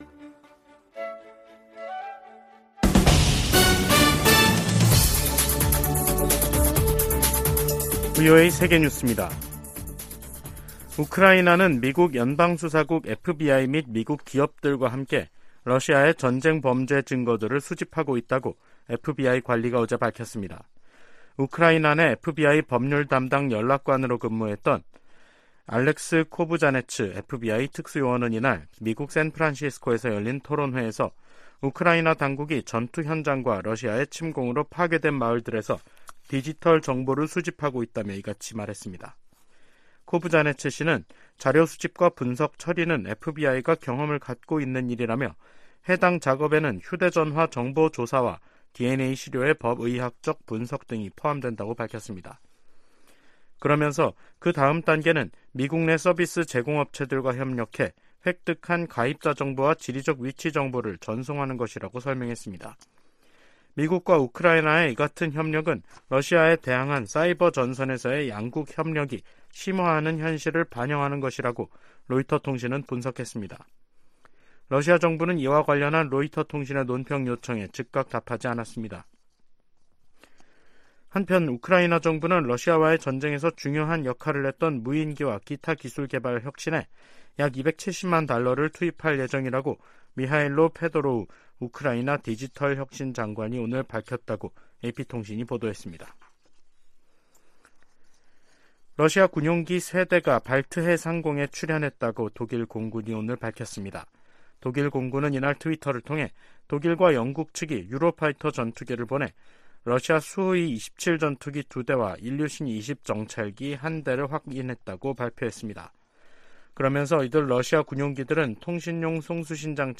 VOA 한국어 간판 뉴스 프로그램 '뉴스 투데이', 2023년 4월 26일 2부 방송입니다. 윤석열 한국 대통령이 미국 국빈 방문 이틀째 한국전 참전용사 기념비 참배와 항공우주국 방문 등 일정을 보냈습니다. 미 정부 고위당국자는 미한 정상이 26일 미국의 확장억제 공약을 강화하고 한국의 비확산 의무를 재확인하는 선언을 발표할 것이라고 밝혔습니다.